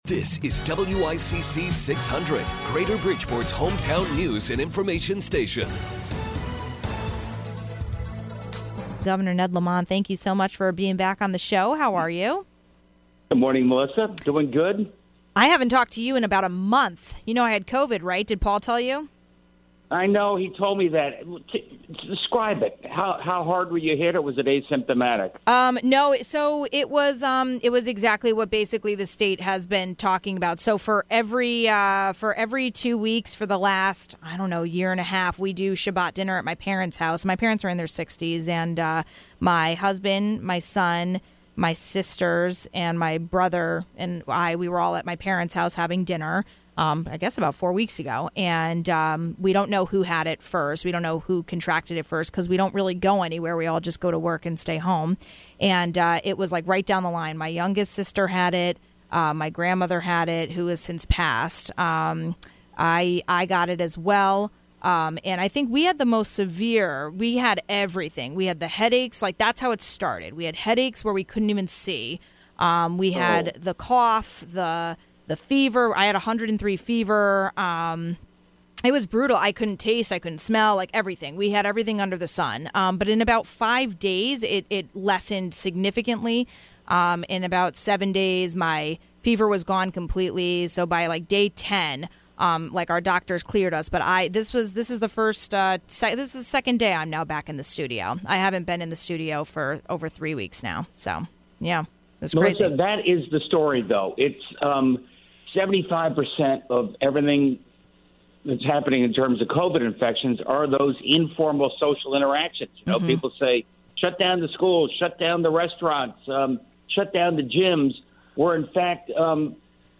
Governor Lamont talks about the latest in covid news for the state of Connecticut. We talked about the vaccine distribution and how we are paying for that.